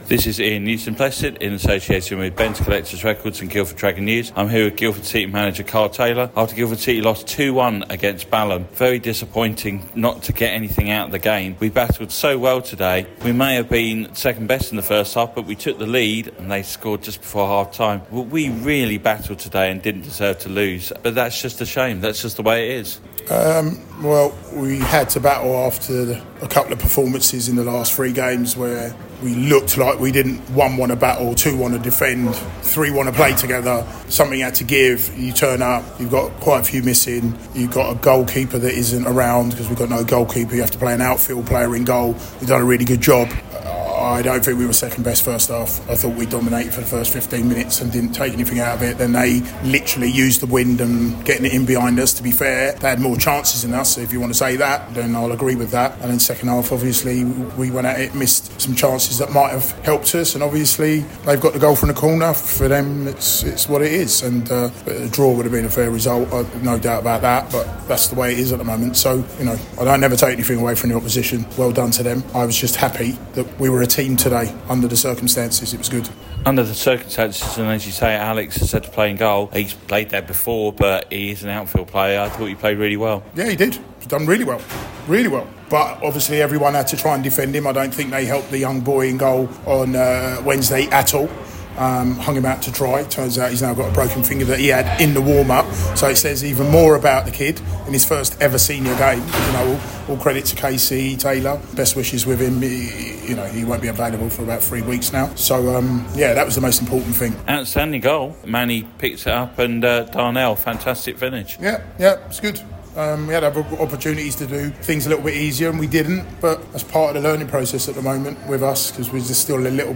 post-match interview